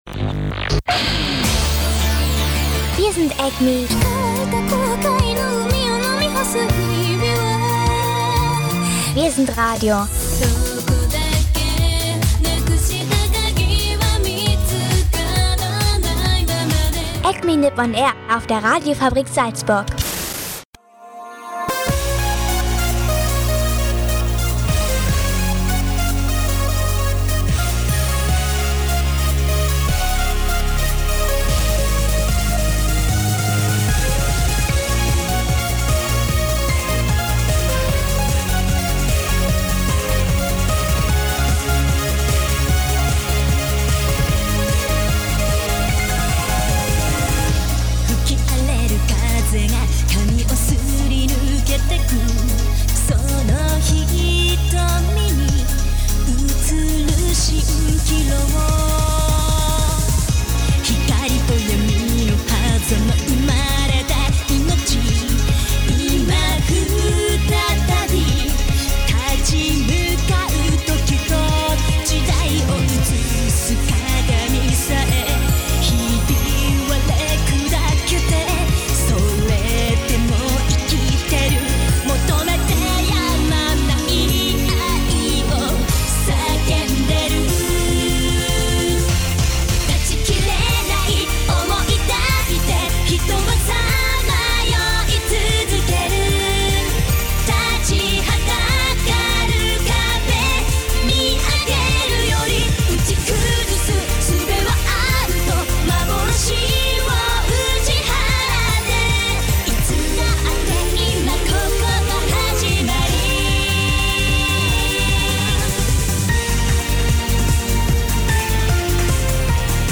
Dazu gibts natürlich Musik aus Japan (und einen Ausrutscher in die Volksmusik), ebenso wie weitere News aus Japan und der Anime.Szene. Mehr